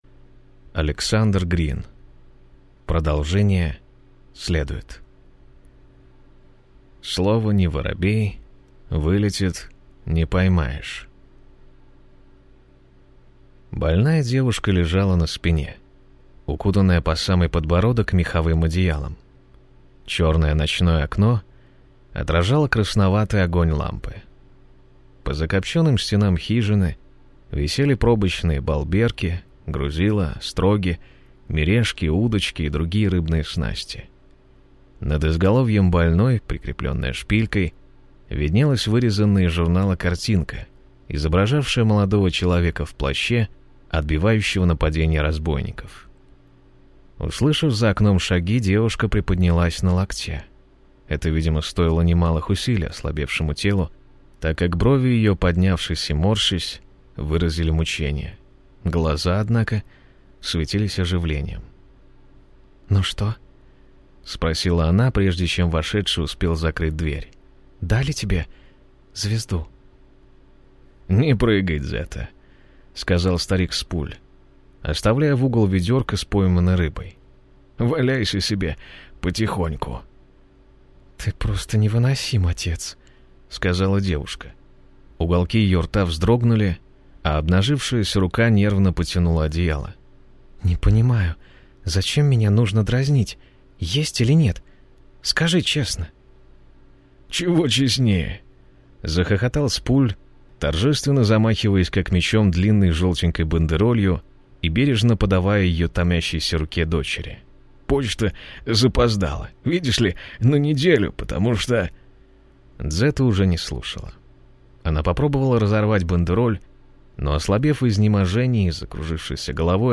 Аудиокнига Продолжение следует | Библиотека аудиокниг
Прослушать и бесплатно скачать фрагмент аудиокниги